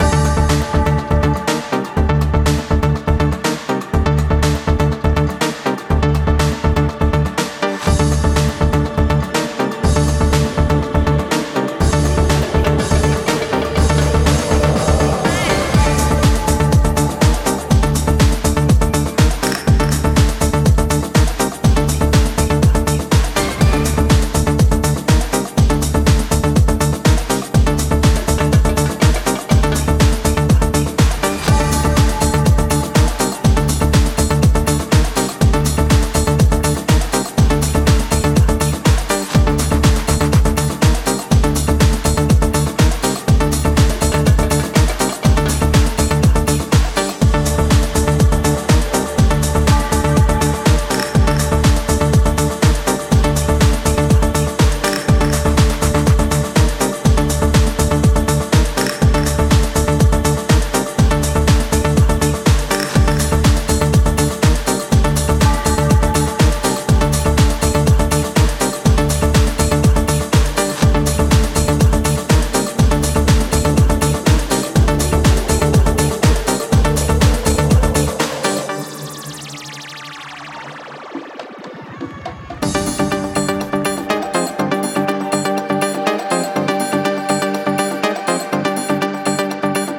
パンチの効いたピークタイム仕様のハウス路線をアッパーに行き過ぎないさじ加減で展開していく
80's〜90'sフィーリングをセンス良く現代的に取り入れながらフロアを熱いエナジーで満たしていく大推薦盤です！